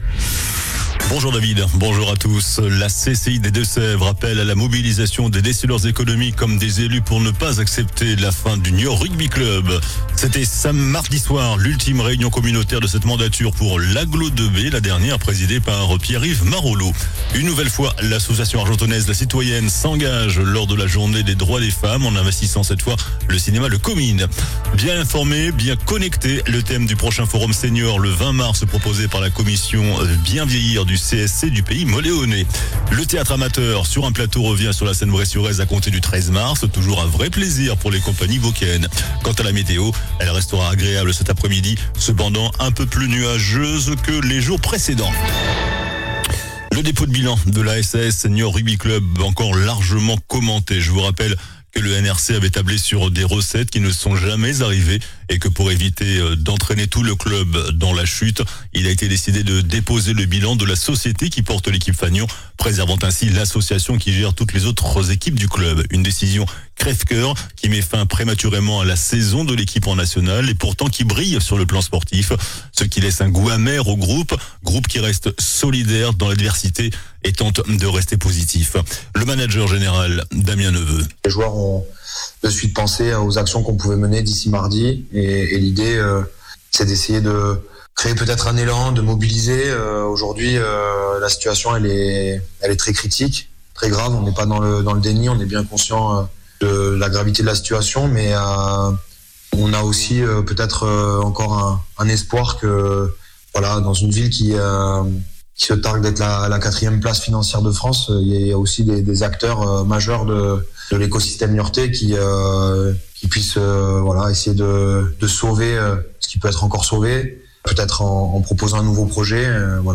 JOURNAL DU JEUDI 05 MARS ( MIDI )